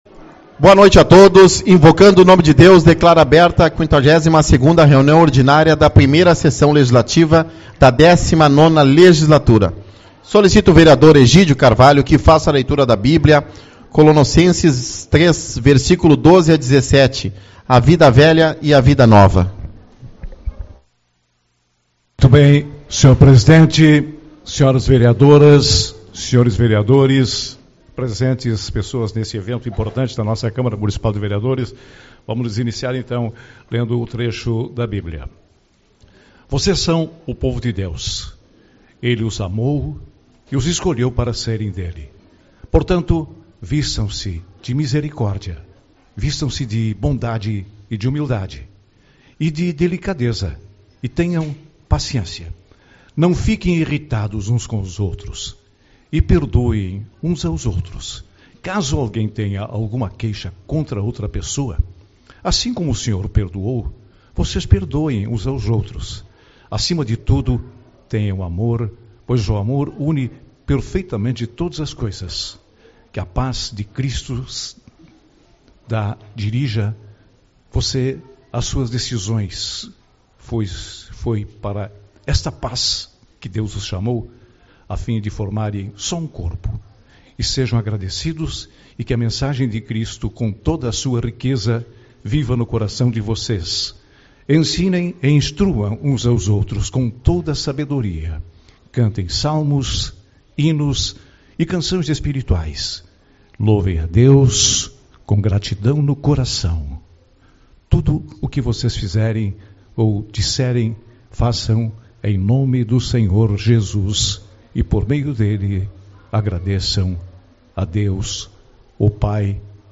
26/08 - Reunião Ordinária - Bairro Santo Inácio